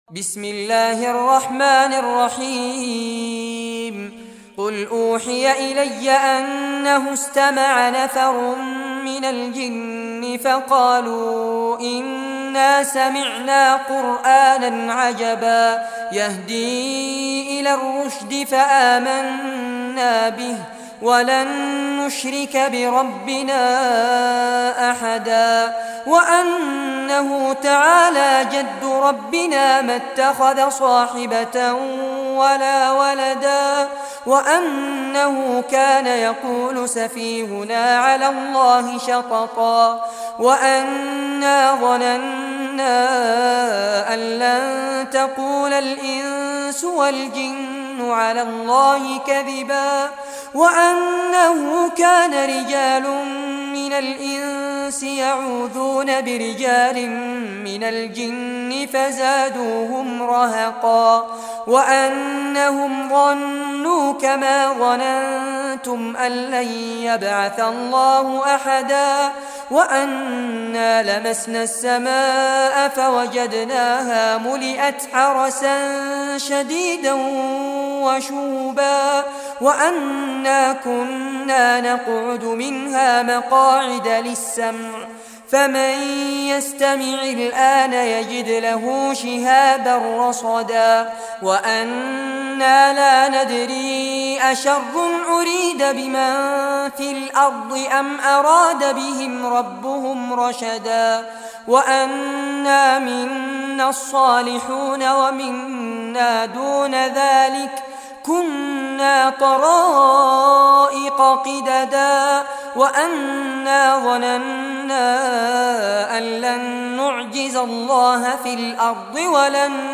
Surah al-Jinn Recitation by Fares Abbad
Surah al-Jinn, listen or play online mp3 tilawat / recitation in Arabic in the beautiful voice of Sheikh Fares Abbad.